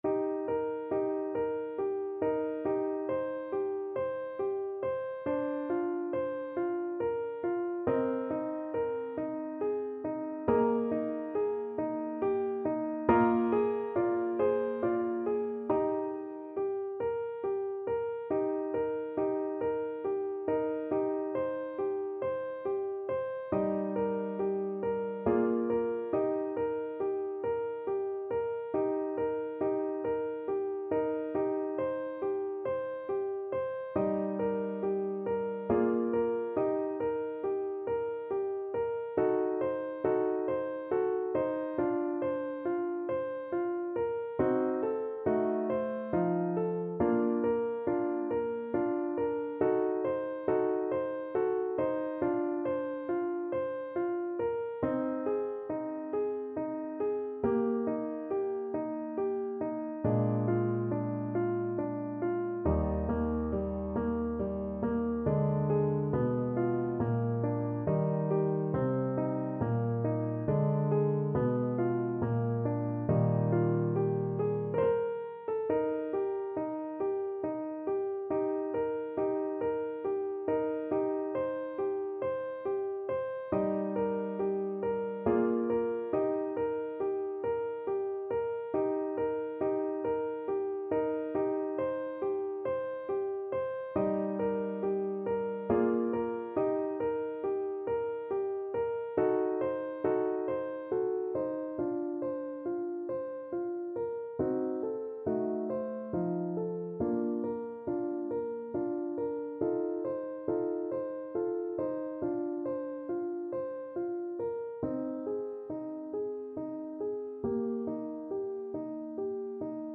3/8 (View more 3/8 Music)
Eb major (Sounding Pitch) (View more Eb major Music for Voice )
= 69 Langsam
Classical (View more Classical Voice Music)